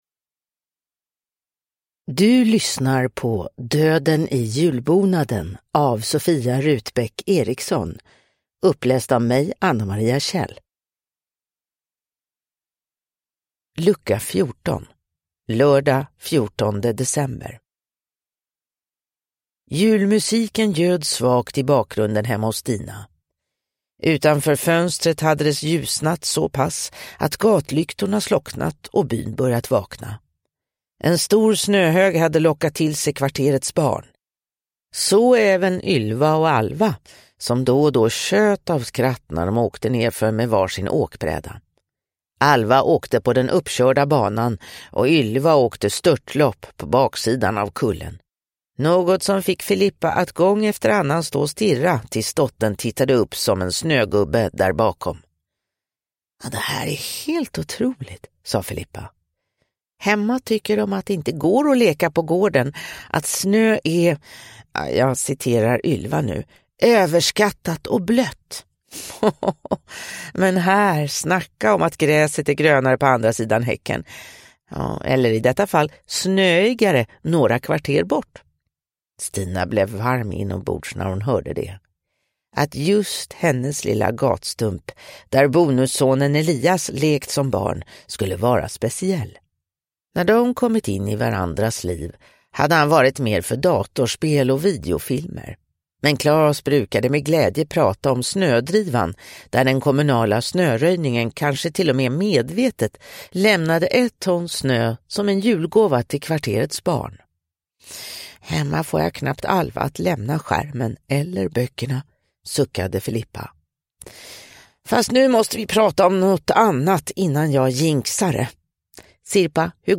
Döden i julbonaden: Lucka 14 – Ljudbok